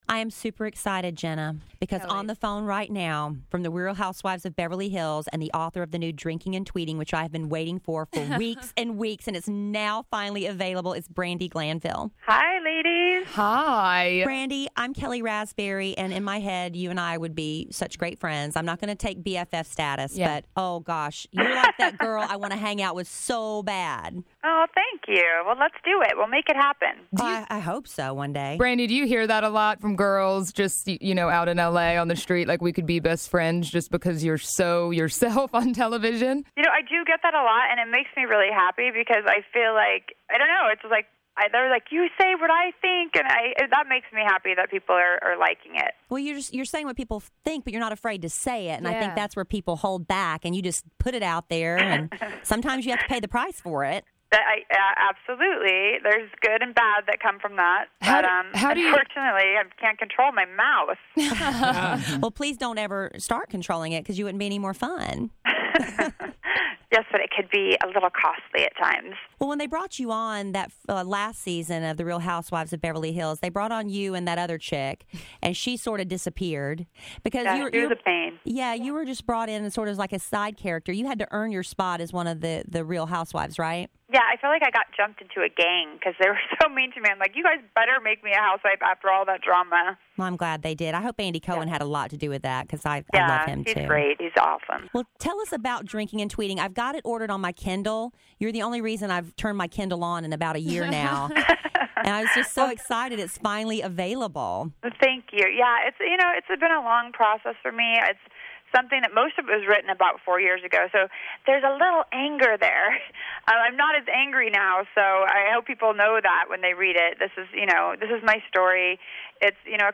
Brandi Glanville Interview
Kidd Kraddick in the Morning interviews Brandi Glanville from The Real Housewives of Beverly Hills.